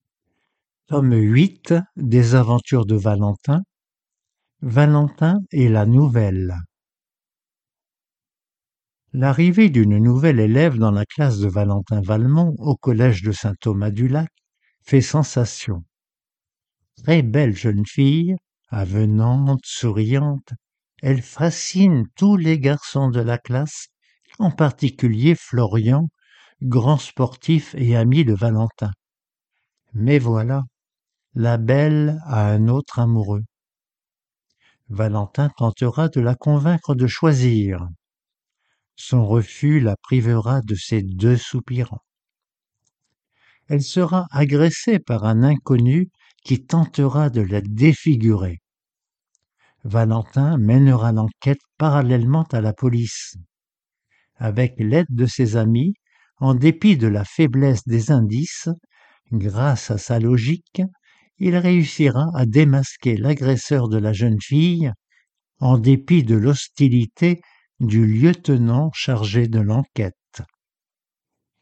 Romans audios pour adultes mal-voyants